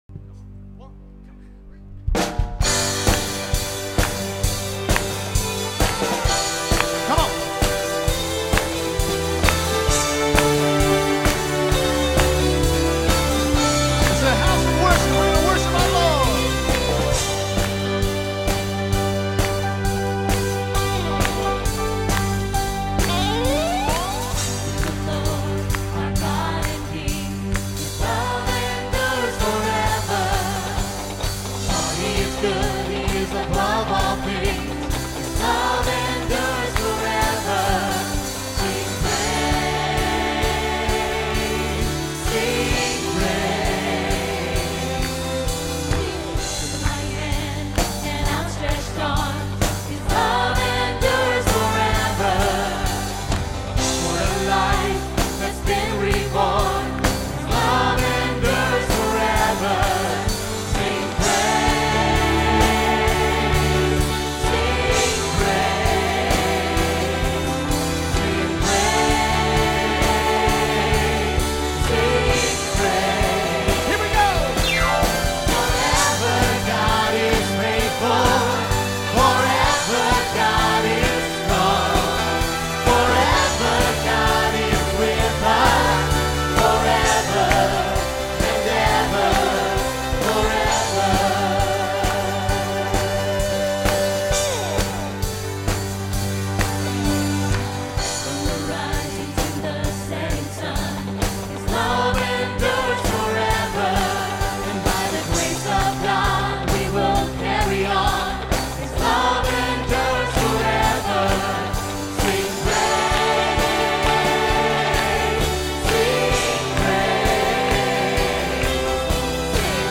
1. The sound mix will not always be well balanced. The recordings were taken off the "house mix", which means that adjustments made for "stage volume" and "room EQ" have an impact on the recording. Unfortunately, that often means the bass gets turned down as a result of someone having the bass too high in their monitor, or any other random boominess that shows up.
2. You often get imprecise starts and stops to the songs as that is dependent upon the sound man hitting the button to tell the recorder to start a new track. We often go from one song right into another, so this can be a difficult task.